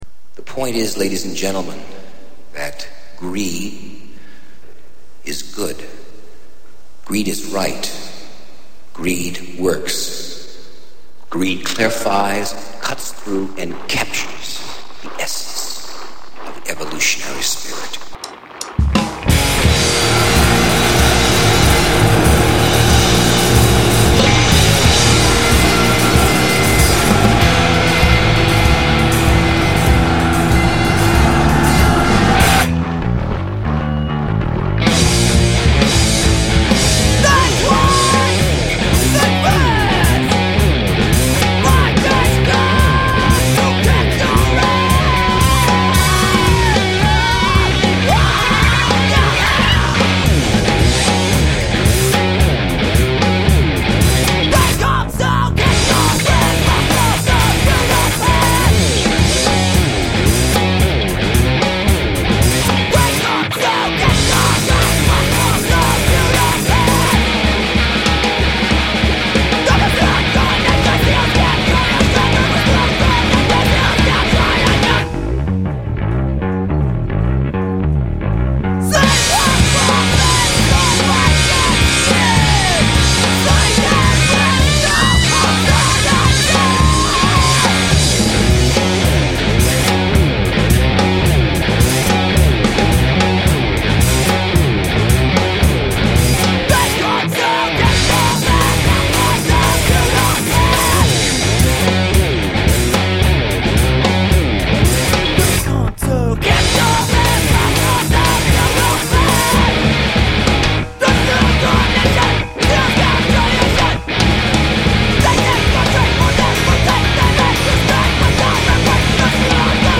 bass and drums